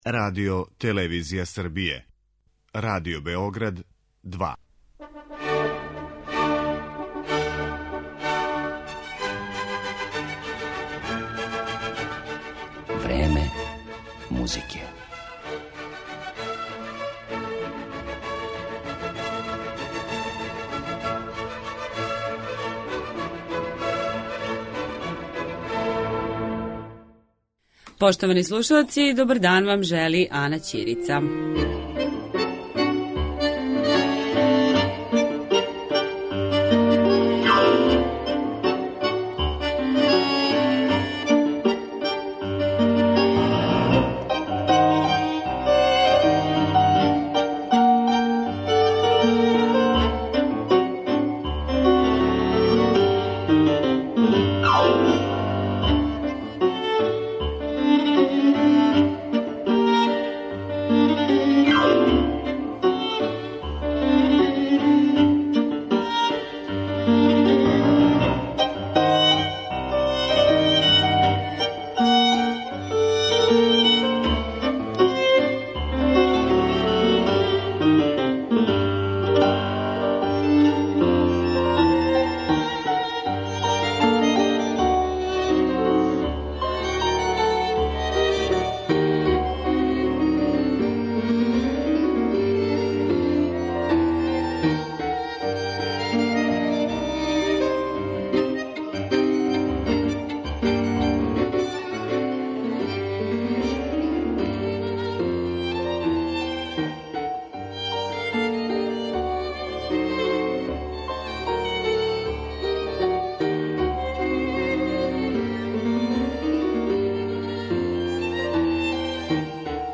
Libercuatro tango ensemble је специјализован за извођење танго музике Пјацоле, Гардела, Троила, Виљолда, Канара и других аутора.